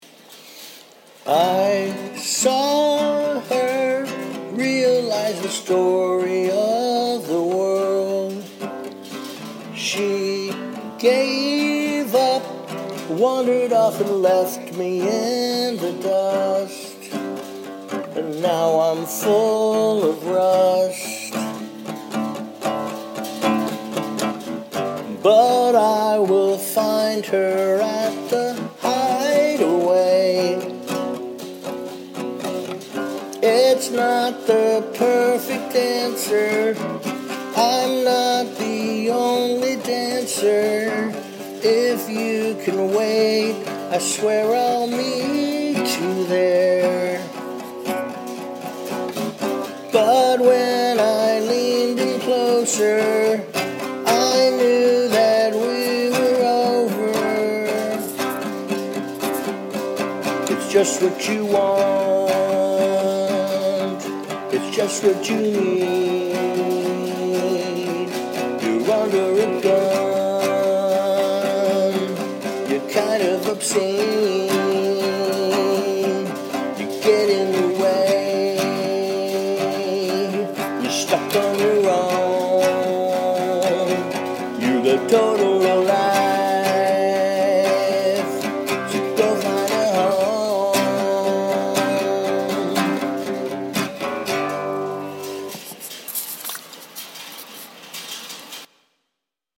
So aside from some dodgy singing, I mean come on isn't this chorus kind of good?